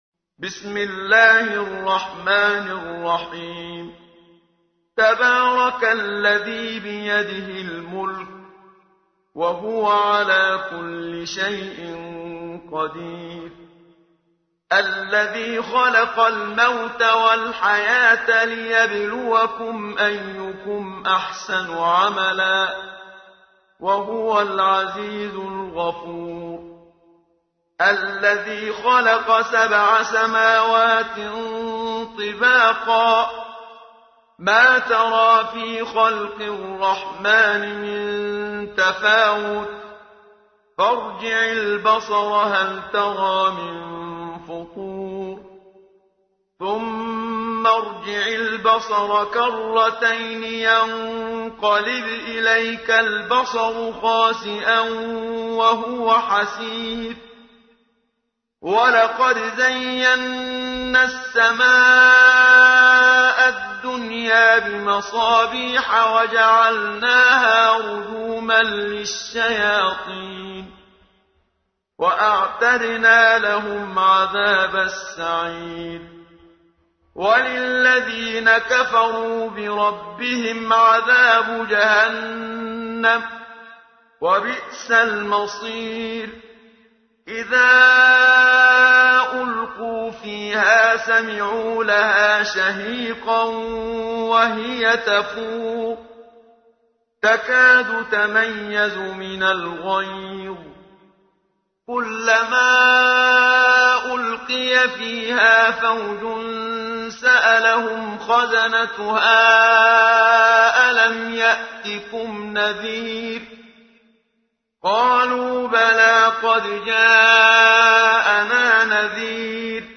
همه چیز در مورد سوره ملک + ترتیل استاد منشاوی